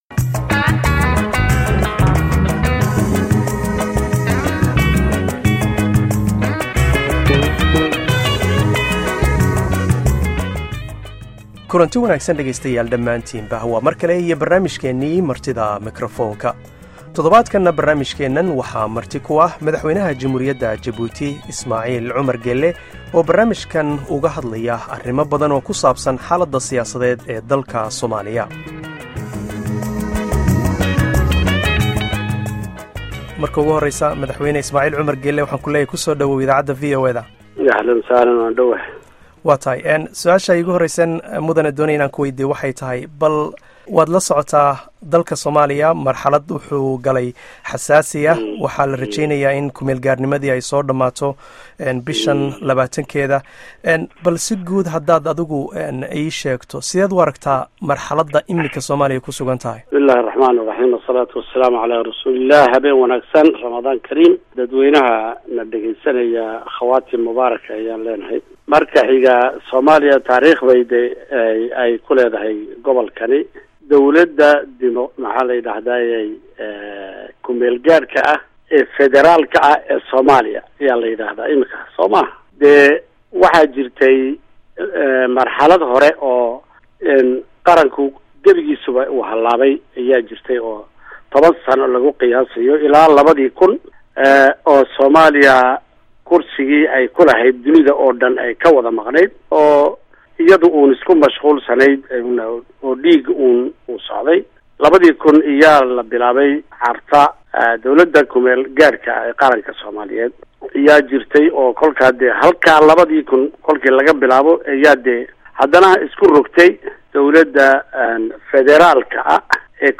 Madaxweynaha Djibouti, Ismaacil Cumar Geelle ayaa marti ku ah barnaamijka Martida Makrafoonka, wuxuuna ka hadlayaa xaaladda siyaasadeed ee Somalia.